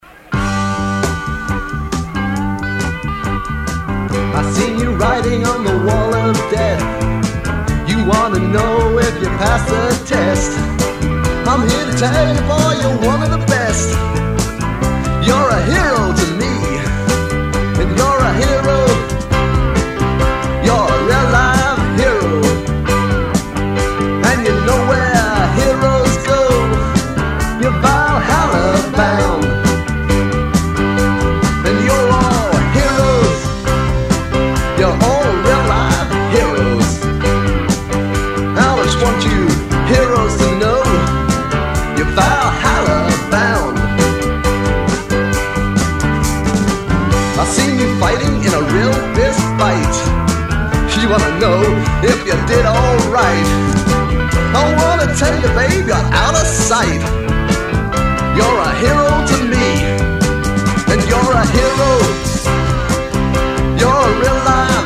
re-mastered from the original tapes